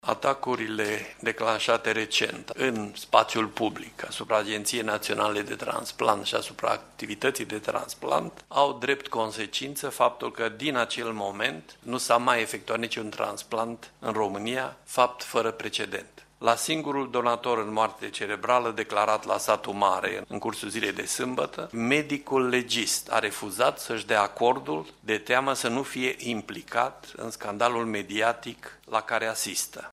Situația este fără precedent – a susţinut ieri conducerea Agenţiei Naţionale de Transplant, într-o conferinţă de presă.
Directorul executiv al agenţiei de transplant, Radu Deac, a spus că afirmaţiile denigratoare sau calomnioase induc panică în rândul pacienţilor şi fac mai dificilă obţinerea consimţământului de donare, ceea ce va duce la creşterea mortalităţii: